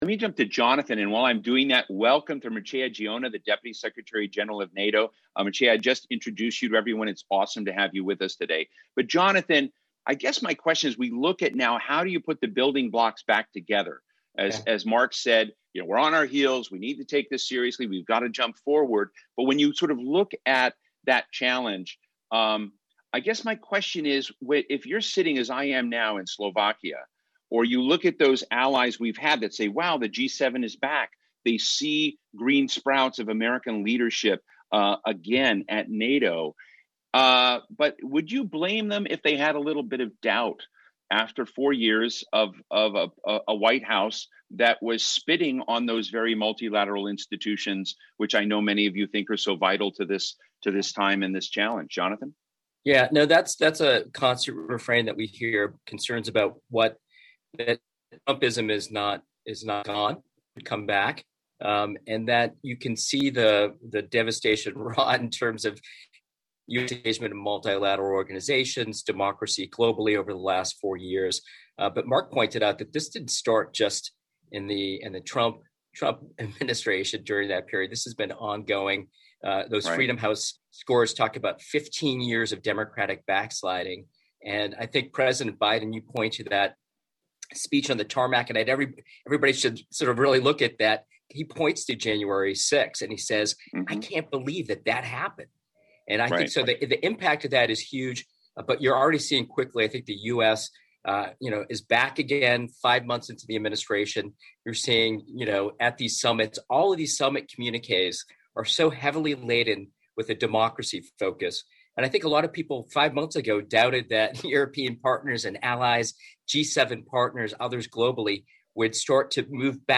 Deputy Secretary General Mircea Geoană underlined NATO’s important role in protecting democratic values in a panel discussion at the German Marshall Fund’s Brussels Forum on Thursday (17 June 2021).